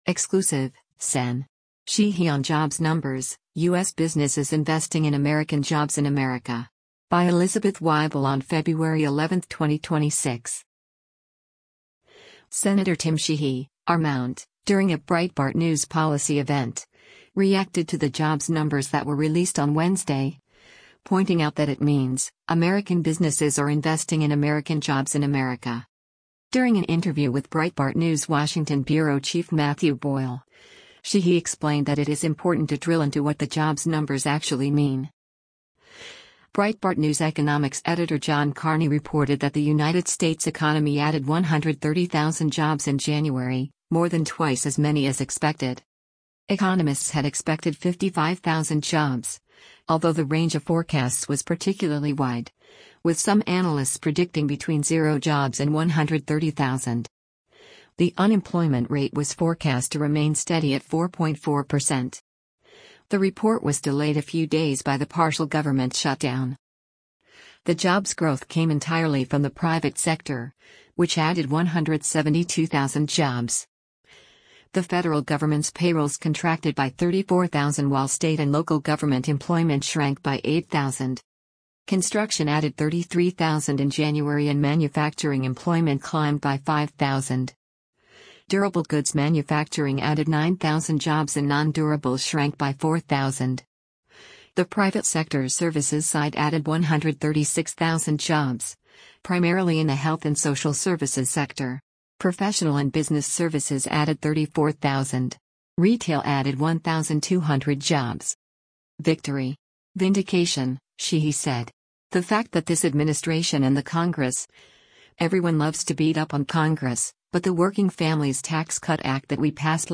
Breitbart News interview with Sen. Tim Sheehy
Sen. Tim Sheehy (R-MT), during a Breitbart News policy event, reacted to the jobs numbers that were released on Wednesday, pointing out that it means, “American businesses are investing in American jobs in America.”